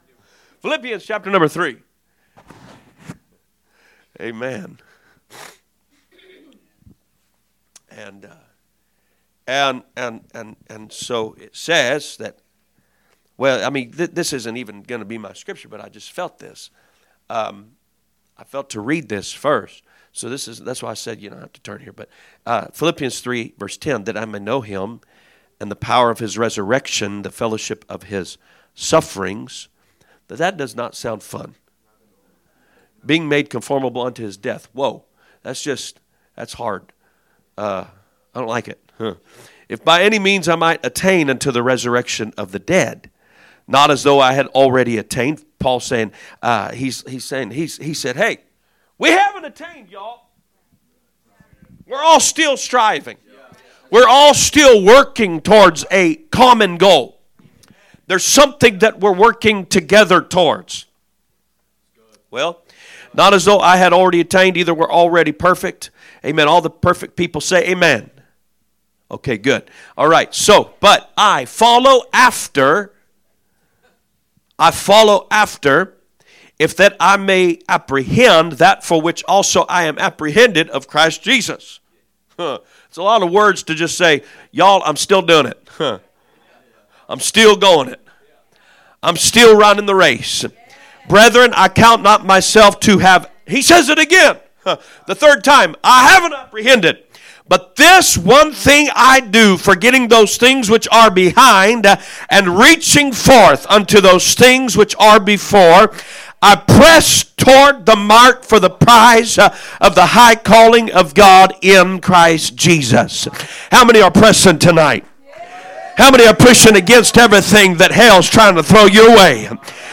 A message from the series "Guest Speakers." 5/20/2025 Tuesday Service
From Series: "2025 Preaching"